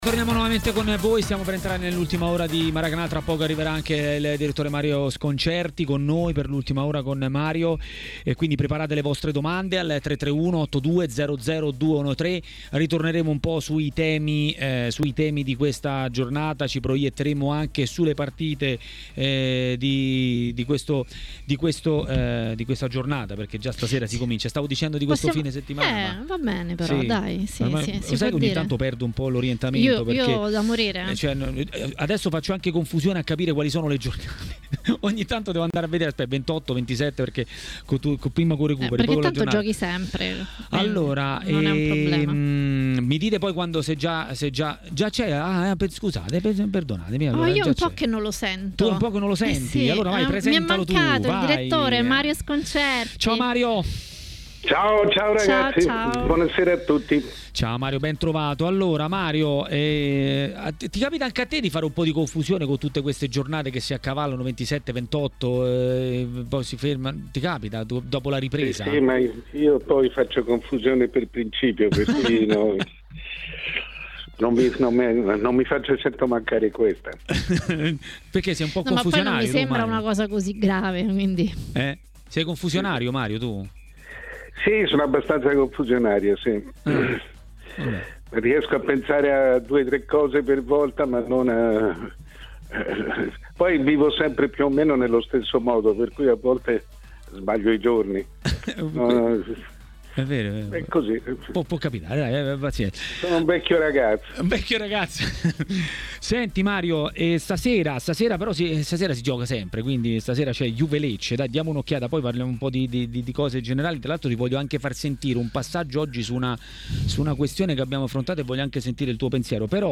Il direttore Mario Sconcerti ha parlato di alcuni temi del calcio italiano a TMW Radio, durante la trasmissione Maracanà